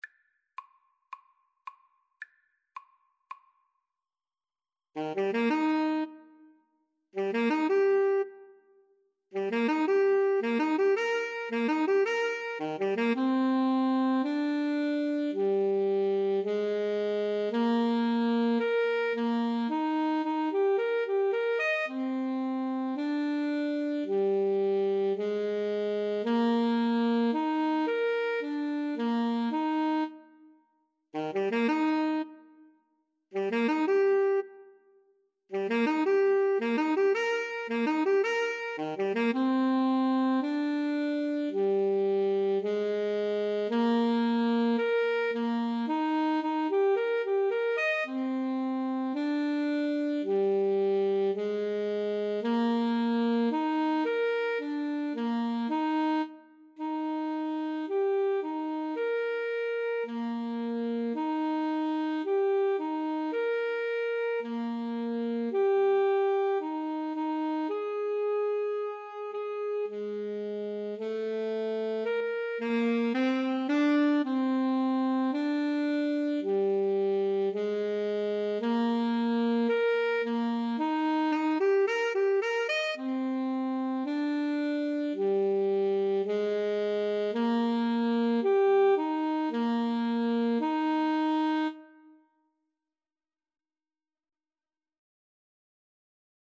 ClarinetAlto Saxophone
Andante maestoso =c.110 =110